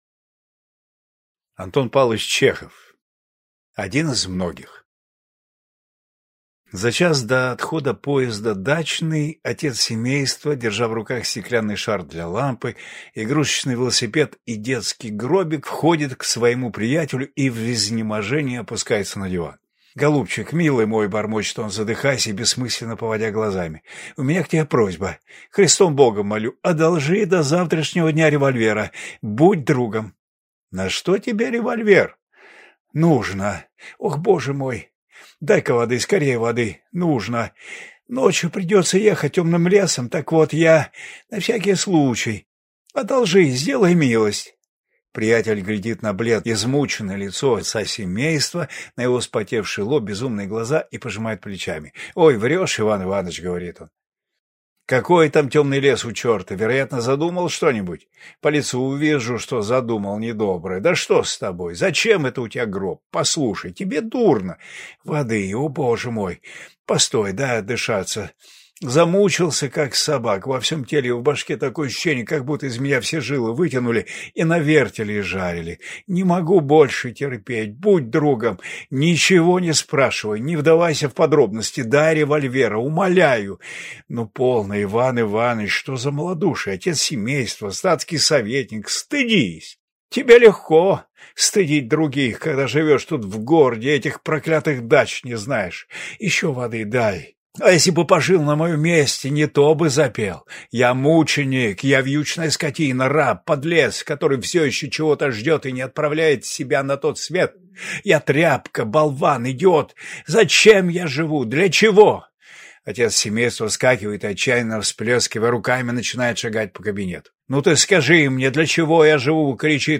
Aудиокнига Один из многих